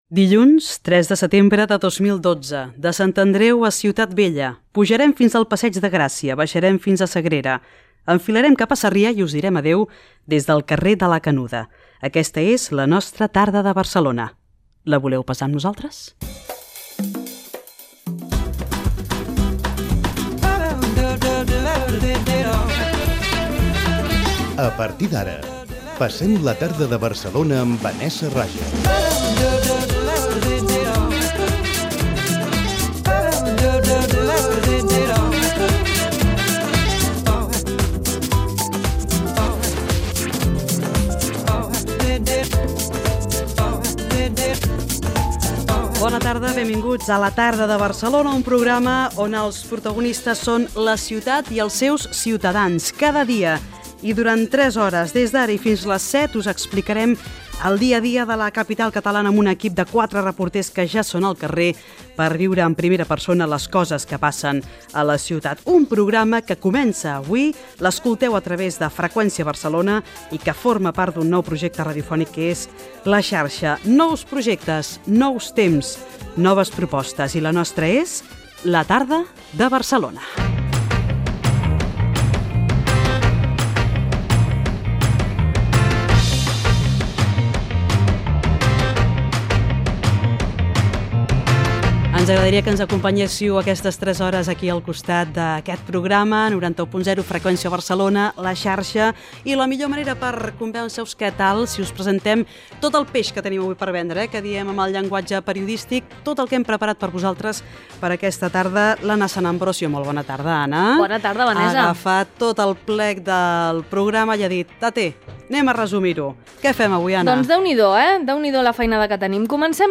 Sumari, careta, presentació del primer programa amb identificació, equip, avançament de continguts, el sector de la perruqueria, preus del servei del Bicing